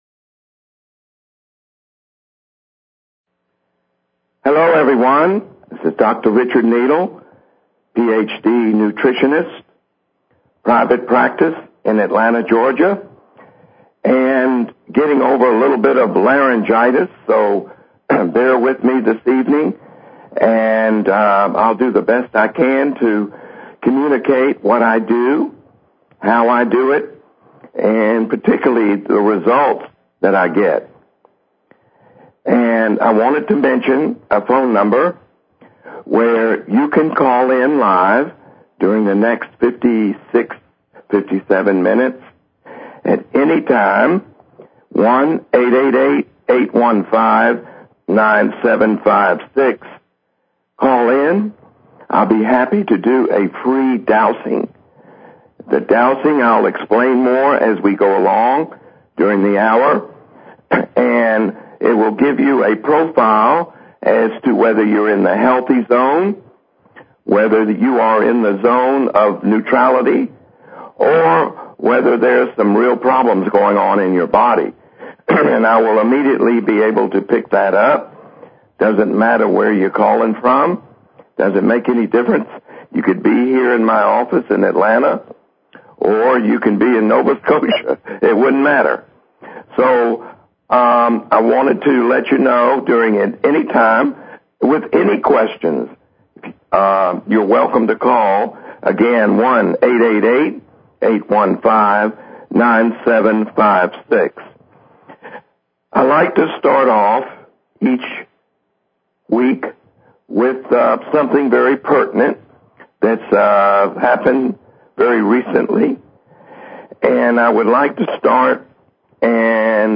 Talk Show Episode, Audio Podcast, Dowsing_for_Health and Courtesy of BBS Radio on , show guests , about , categorized as